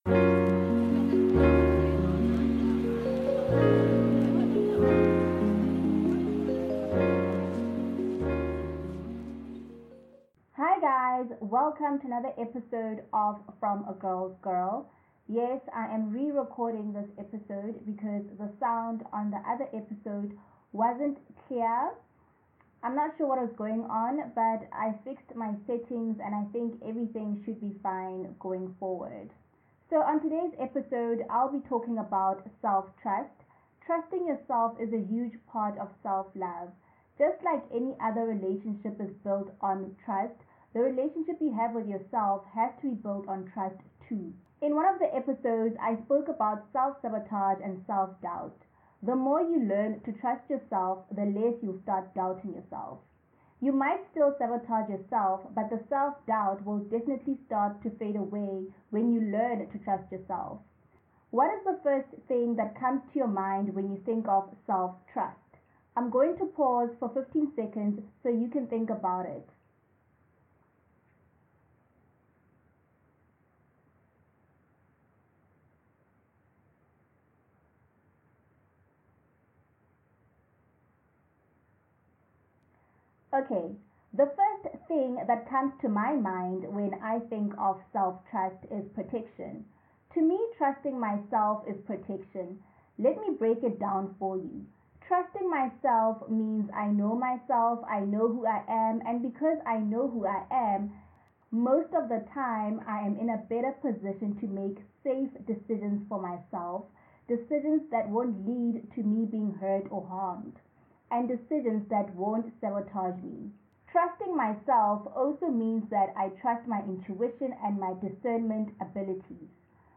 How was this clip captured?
I rerecorded the episode because a lot of you complained about the sound quality of the original episode.